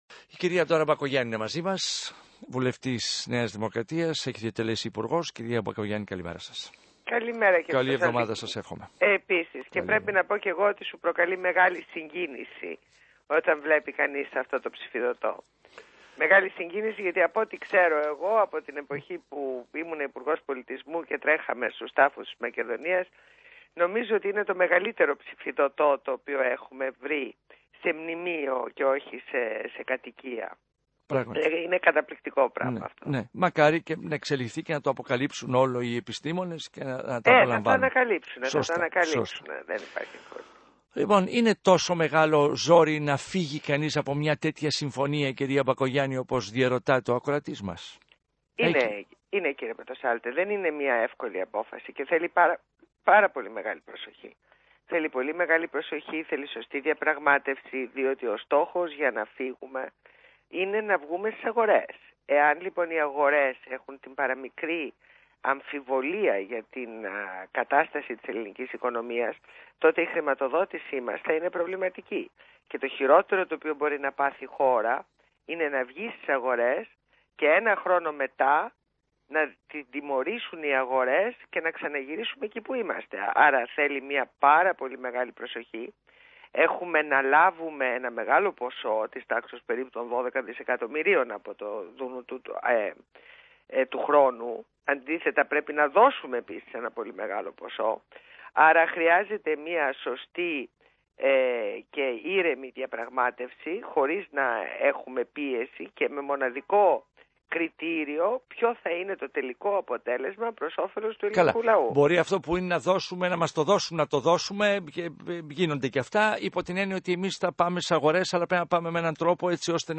Ακούστε τη συνέντευξη στο ραδιόφωνο του ΣΚΑΙ στην εκπομπή του Α. Πορτοσάλτε.